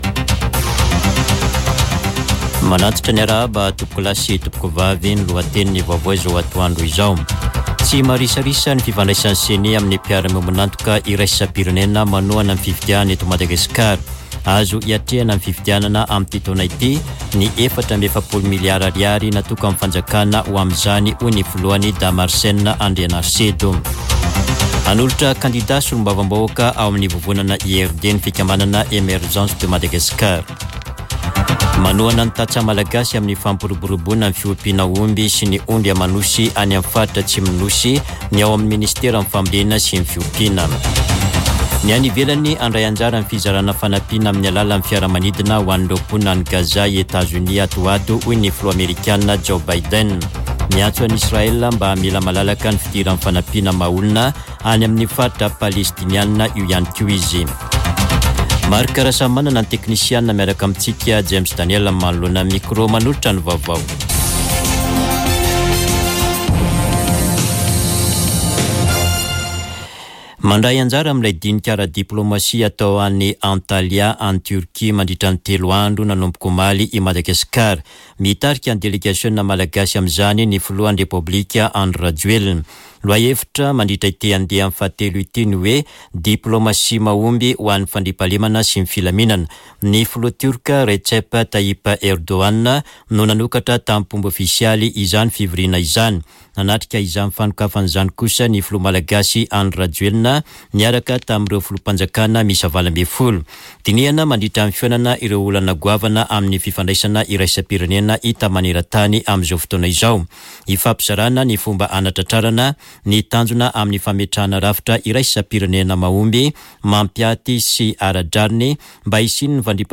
[Vaovao antoandro] Sabotsy 2 marsa 2024